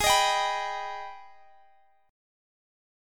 Listen to AbmM7 strummed